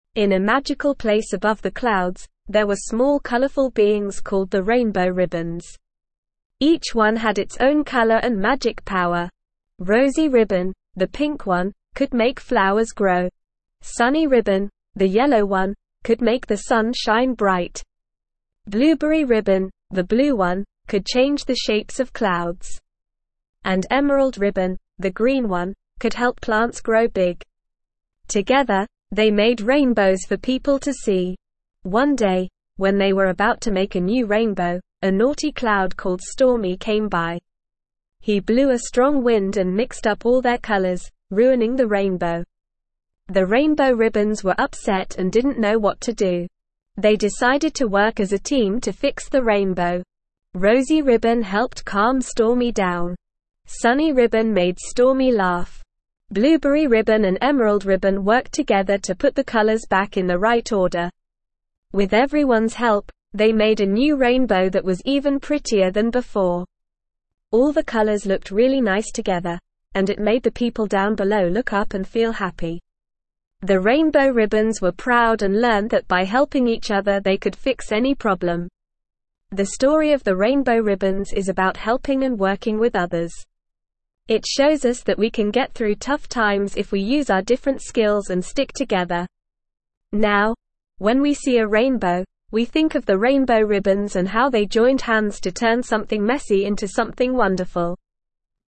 Normal
ESL-Short-Stories-for-Kids-Lower-Intermediate-NORMAL-Reading-Rainbow-Ribbons-in-the-Sky.mp3